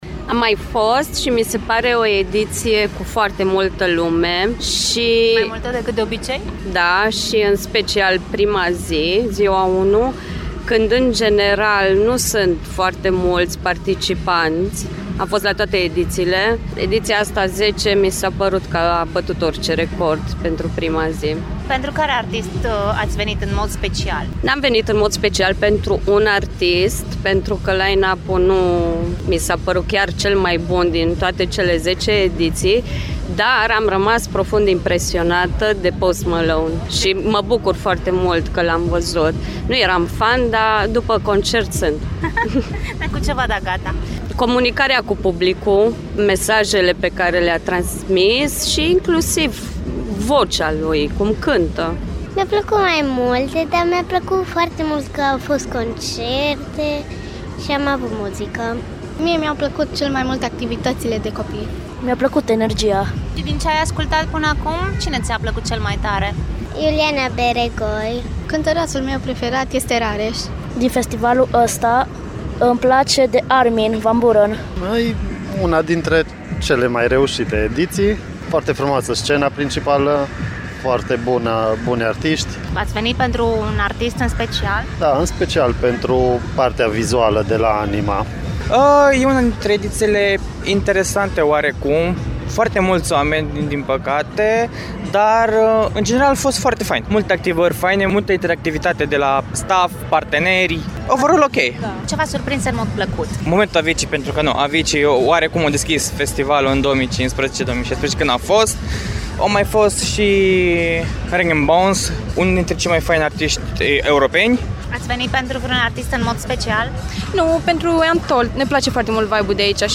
vox-untold-online.mp3